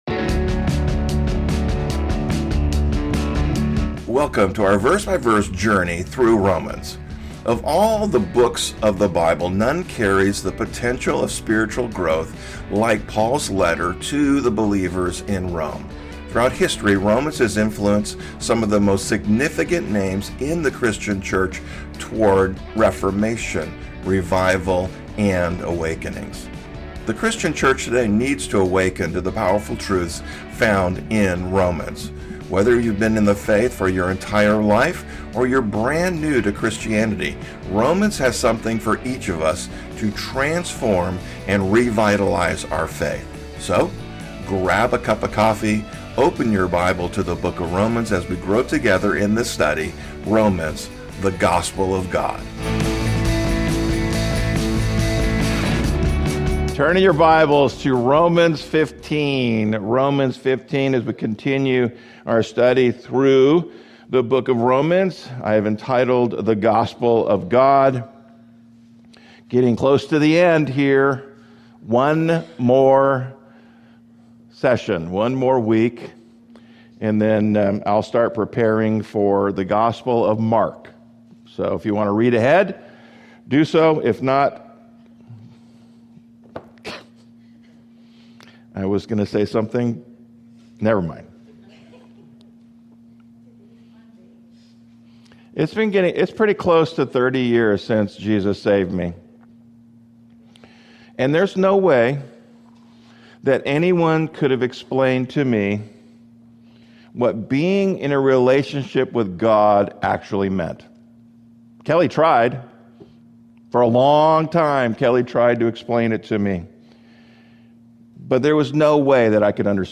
The Sermons - Calvary Chapel French Valley